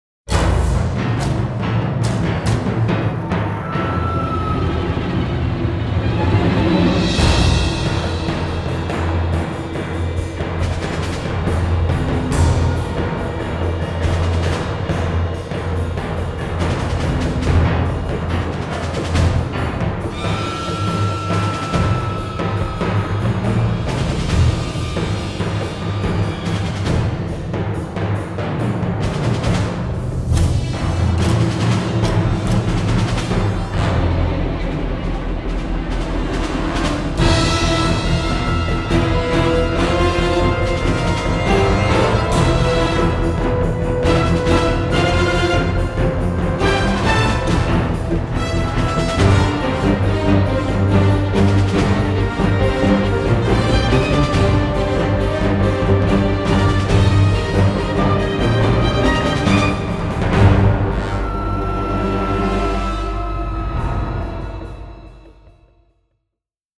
with a tense and modern sheen.